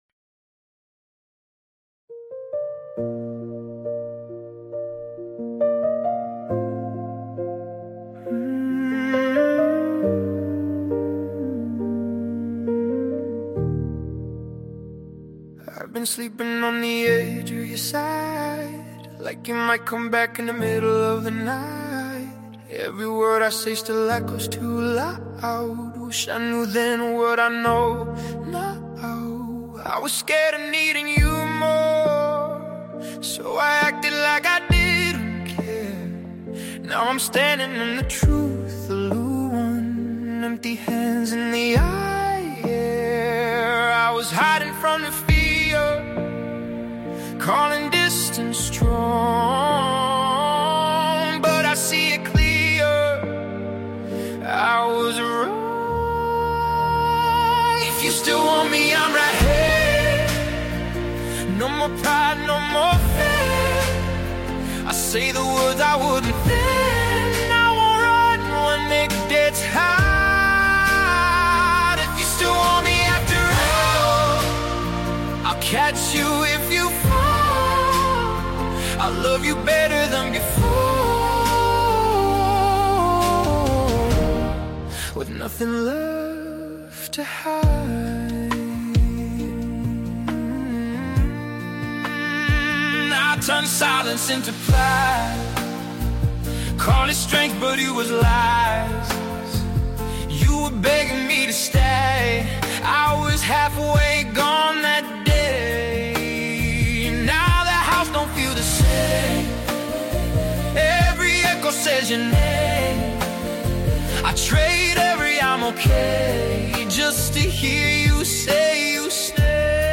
deeply emotional and heartfelt ballad
The gentle instrumentation